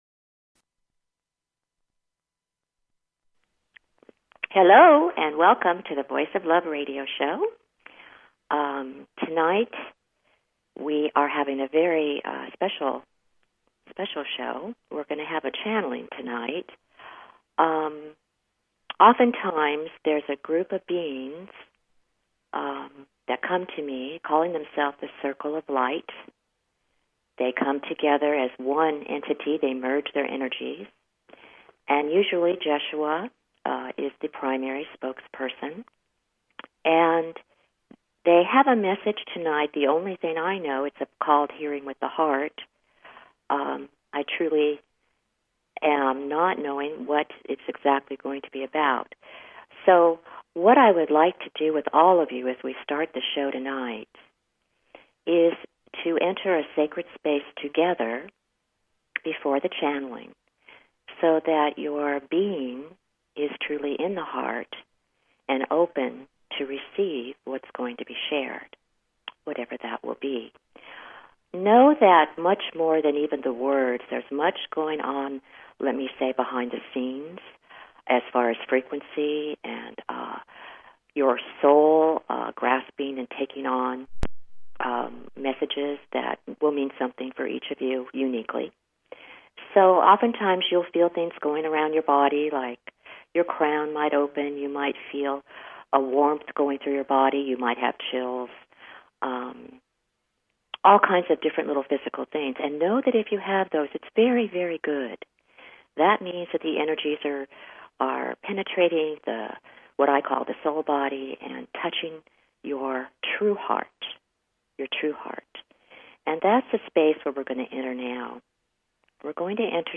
Talk Show Episode, Audio Podcast, The_Voice_Of_Love and Courtesy of BBS Radio on , show guests , about , categorized as